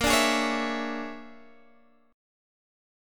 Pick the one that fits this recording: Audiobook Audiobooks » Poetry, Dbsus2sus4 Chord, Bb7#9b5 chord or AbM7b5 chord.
Bb7#9b5 chord